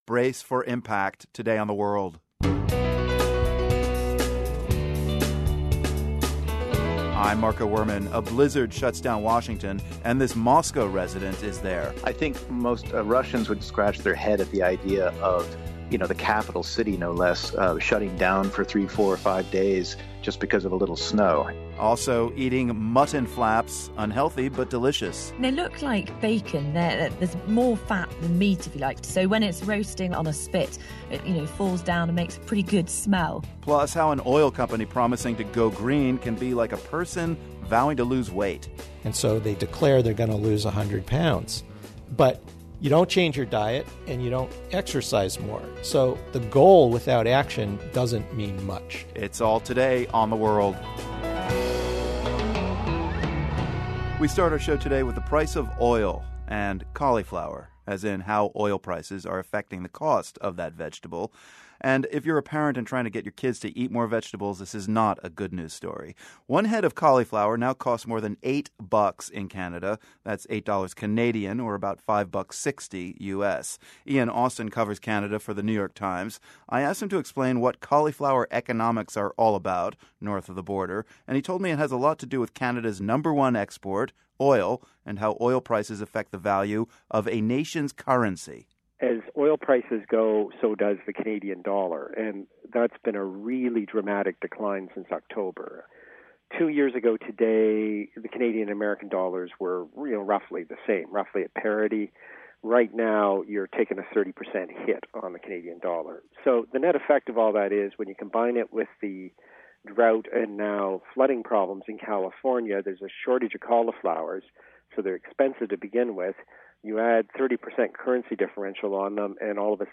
As oil prices sink even lower, how is this affecting the global economy — and how are oil companies preparing for the future? Also, those falling oil prices mean higher prices in Canada, where cauliflower's running about eight bucks a head right now. Plus, with the mid-Atlantic about to get socked by piles of snow, we hear from some Syrian kids who are experiencing their first toboggan ride.